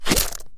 Bubberstation/sound/effects/blob/attackblob.ogg